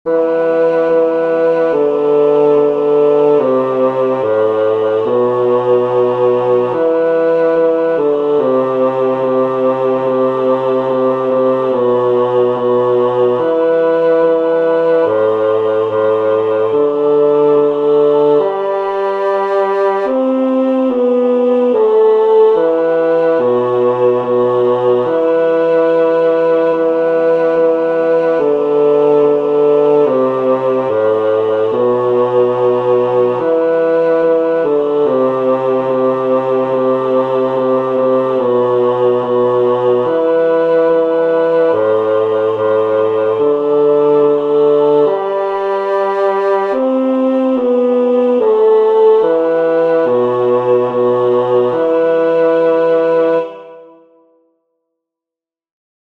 El tempo indicado es Calmo, negra= 72.